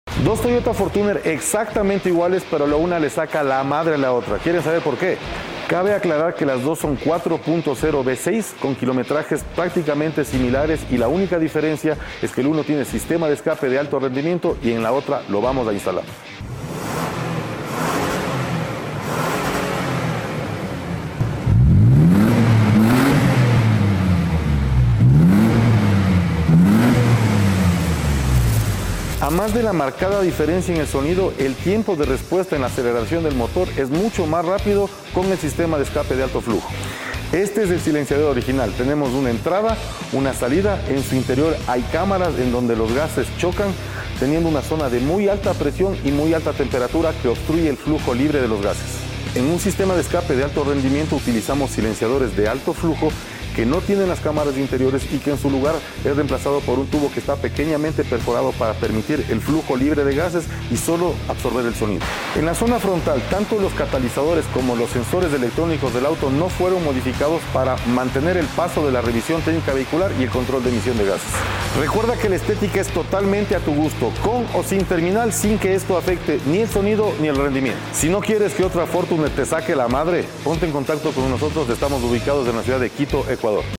Mientras que la versión estándar tiene un sonido más suave y un rendimiento limitado, el sistema de escape de alto flujo mejora notablemente el flujo de gases, lo que aumenta la potencia y mejora la respuesta del motor. Además, el sonido deportivo que logramos le da un toque único a la camioneta.